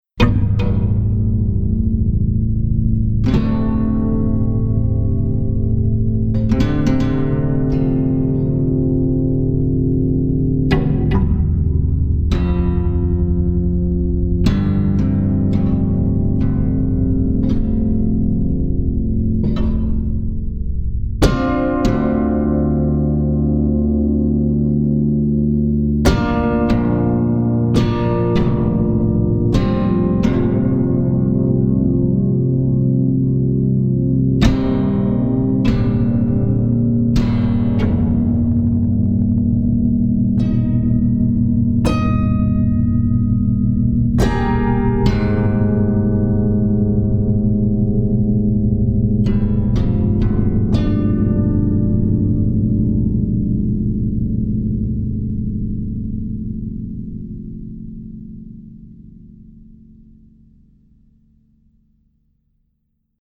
Die Dolceola gibt es neben einer Reihe sanfter bis heller, alt bis modern klingender Versionen auch als dieses aus der Reihe fallende Exemplar, die Dolceola Reckoning: